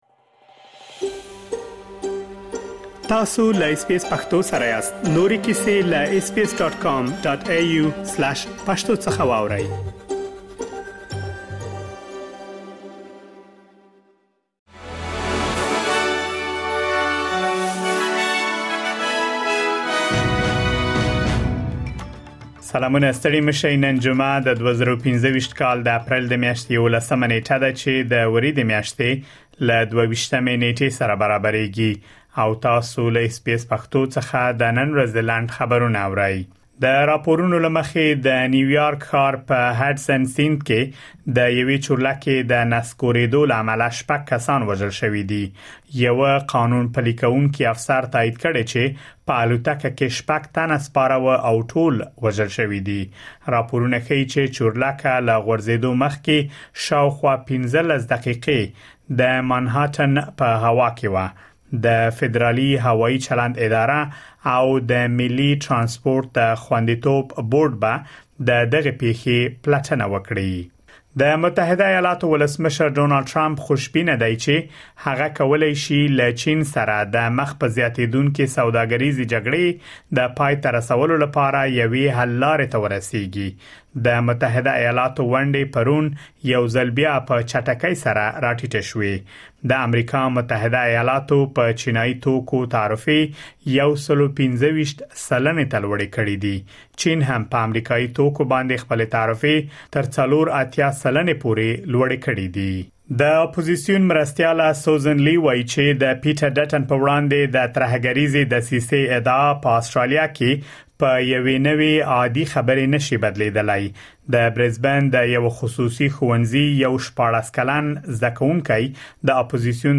د اس بي اس پښتو د نن ورځې لنډ خبرونه | ۱۱ اپریل ۲۰۲۵